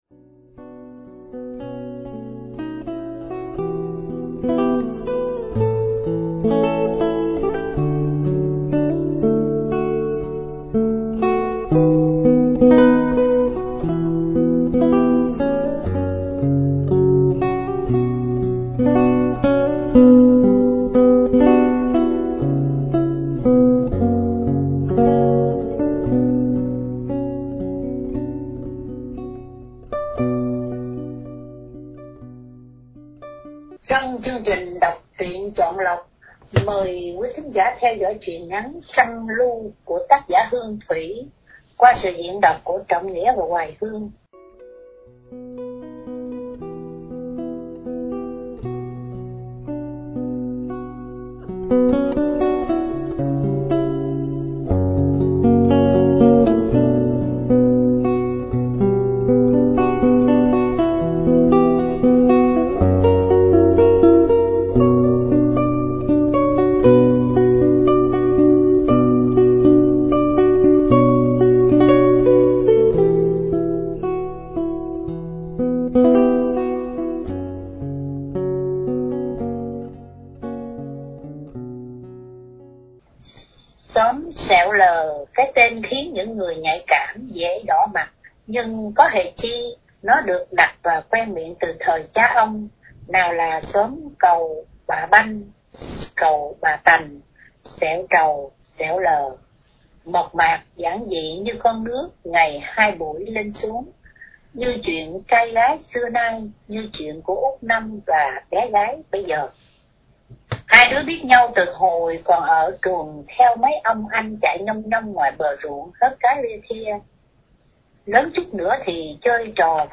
Đọc Truyện Chọn Lọc – Truyện Ngắn ” Trăng Lu”